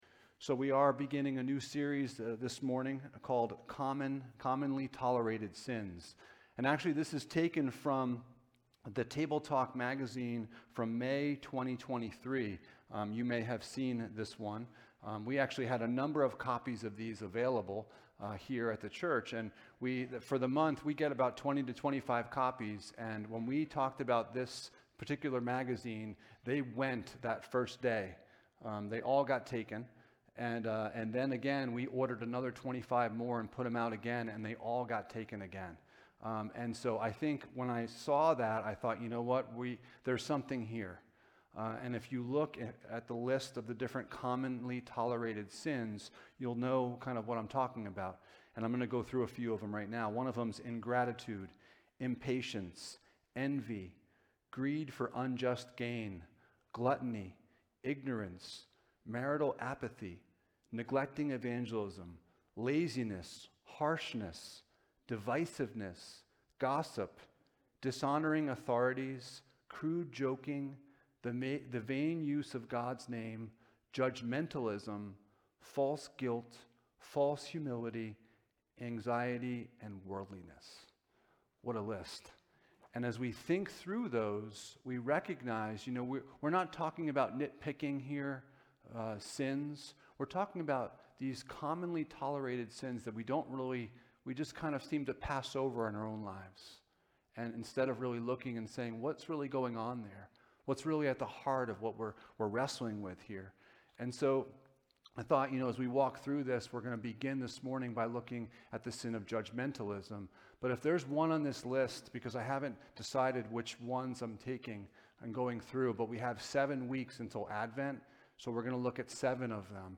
Passage: Matthew 7:1-6 Service Type: Sunday Morning